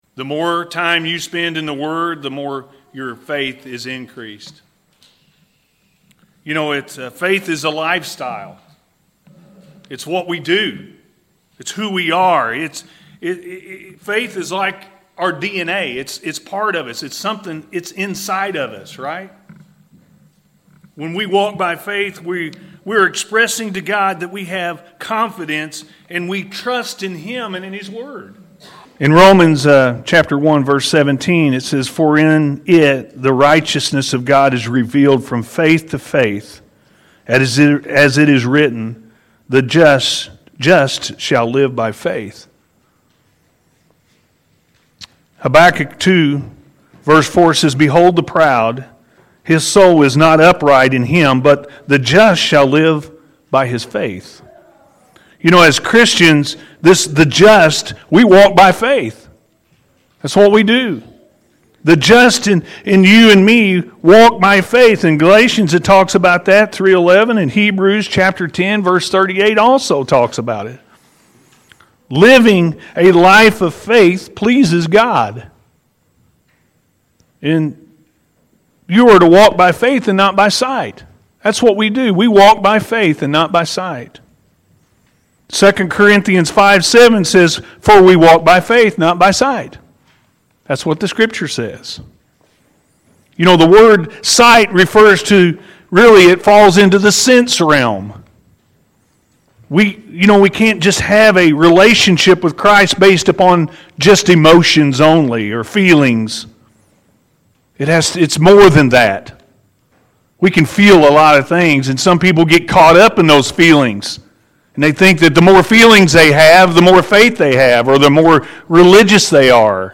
Walk By Faith Not By Sight-A.M. Service